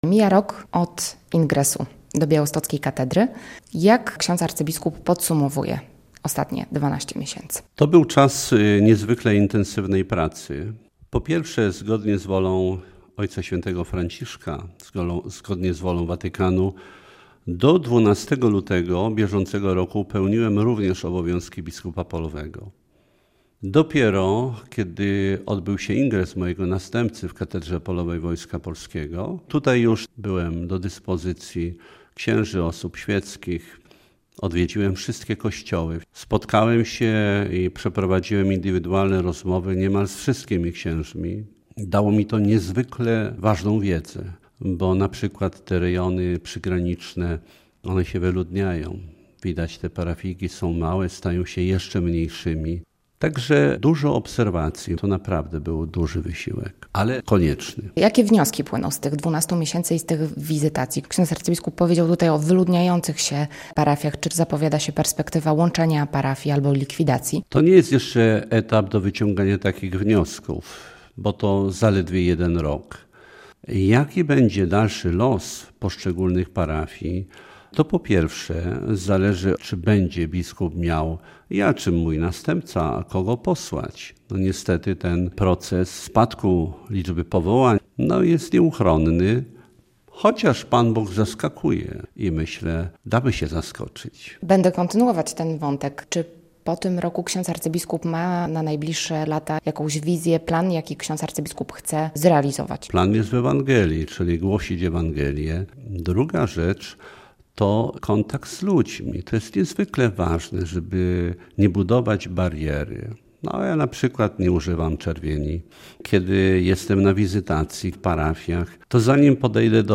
Radio Białystok | Gość | abp Józef Guzdek - metropolita białostocki